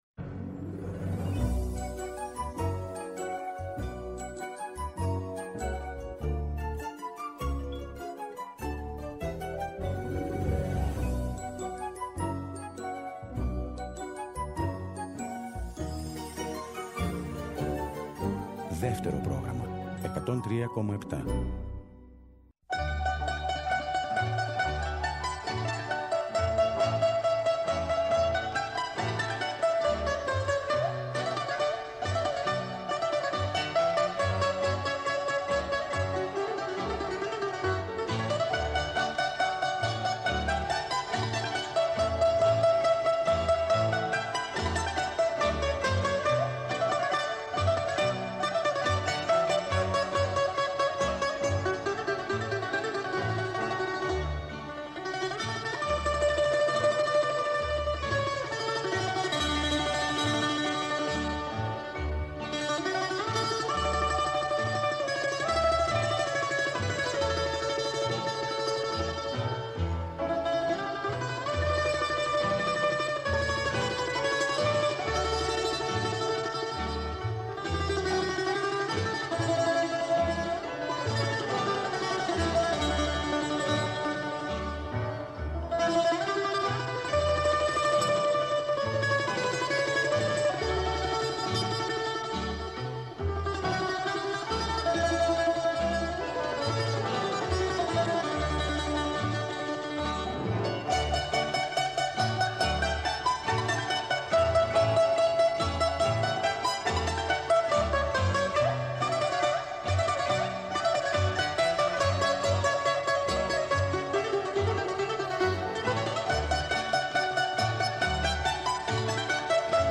Ακούμε σκέψεις, τοποθετήσεις, απόψεις, αλλά και ιστορίες που αφηγείται η ίδια η Μελίνα Μερκούρη.